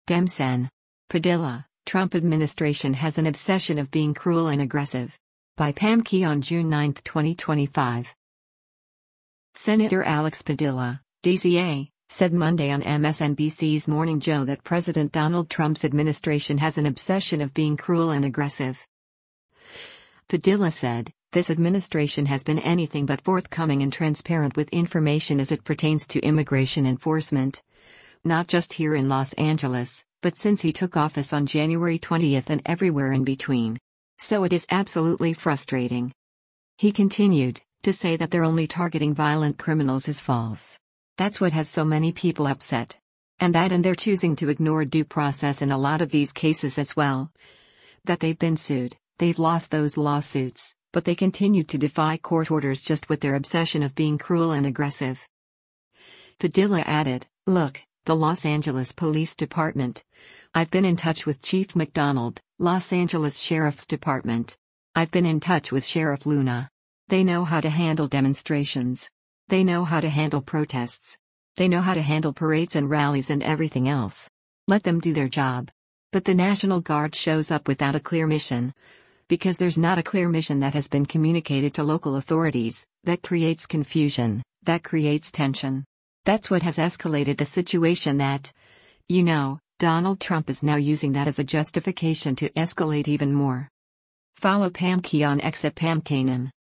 Senator Alex Padilla (D-CA) said Monday on MSNBC’s “Morning Joe” that President Donald Trump’s administration has an “obsession of being cruel and aggressive.”